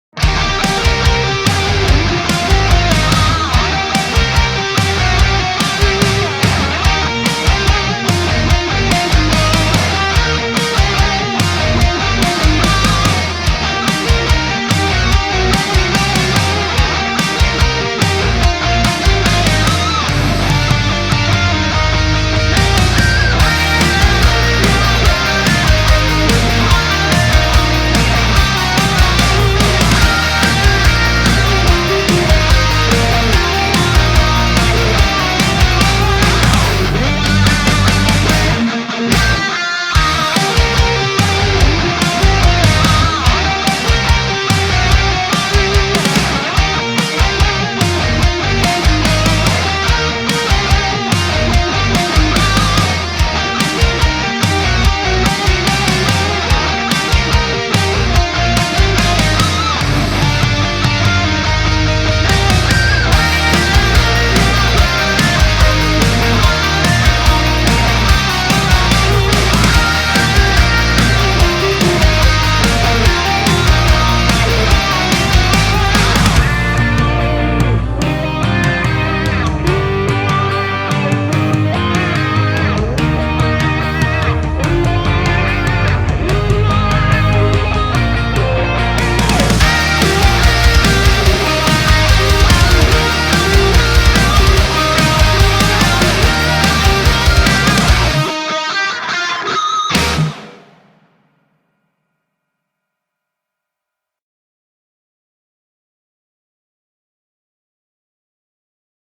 Metal_Rock Guitar Cover Remix